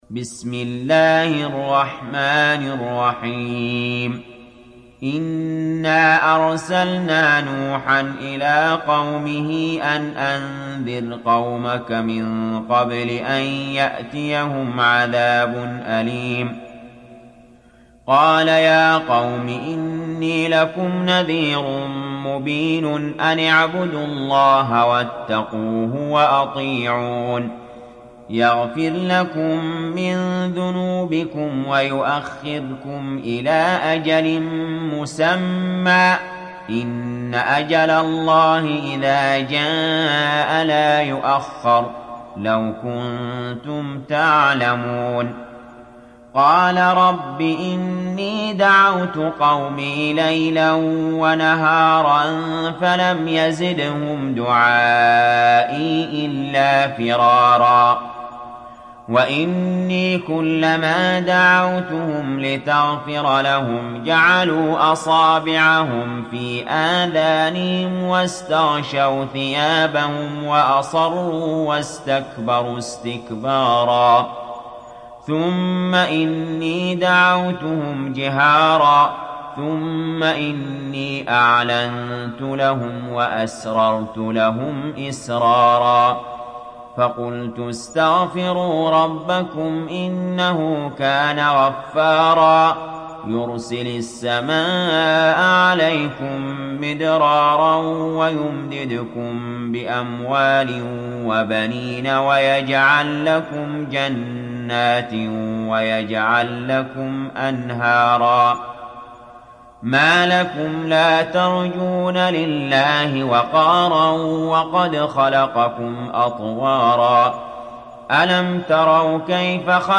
সূরা নূহ ডাউনলোড mp3 Ali Jaber উপন্যাস Hafs থেকে Asim, ডাউনলোড করুন এবং কুরআন শুনুন mp3 সম্পূর্ণ সরাসরি লিঙ্ক